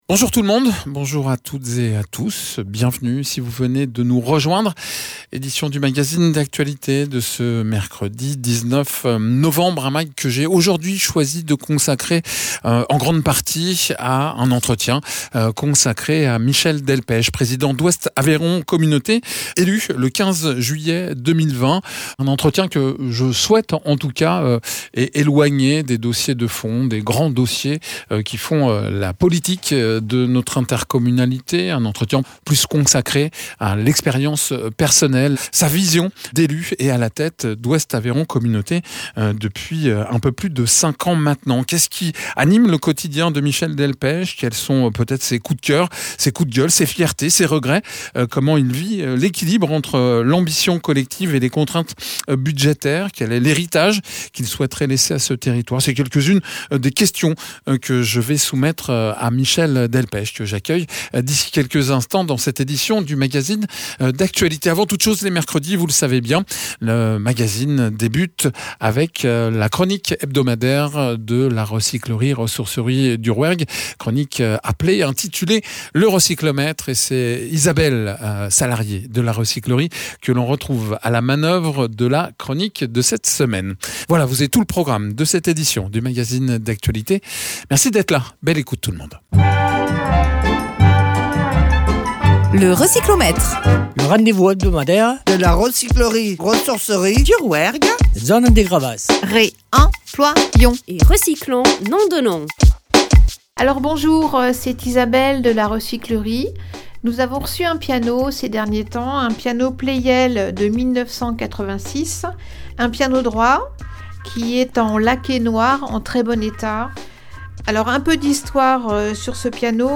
A la suite du rendez-vous hebdomadaire du Recyclomètre, un entretien consacré à Michel Delpech, président d’Ouest Aveyron Communauté. Après un peu plus de 5 ans à la tête de cette intercommunalité, nous faisons avec Michel Delpech un retour d’expérience sur son mandat, son expérience personnelle autour la fonction de président, les défis relevés, les héritages qui vont perdurer et participer au développement de ce territoire.